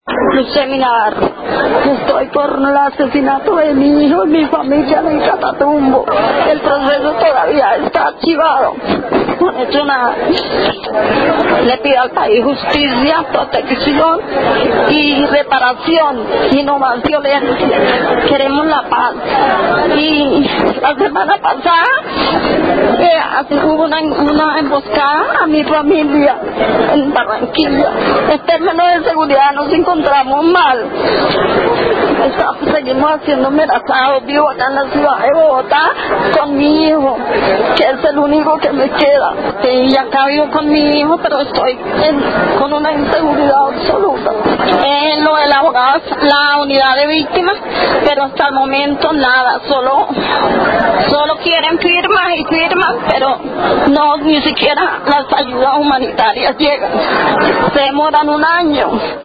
Con lágrimas en sus ojos y voz entrecortada